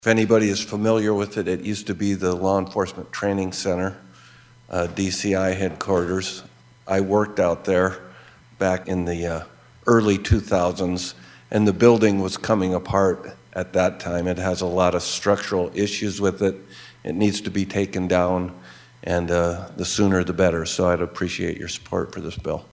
Mehlhaff told colleagues he has first hand knowledge of the building’s terrible condition…